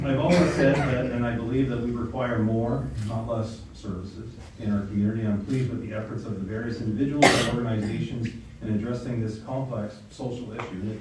A grand opening ceremony for the Renfrew County Mesa HART Hub was held on Thursday, February 5th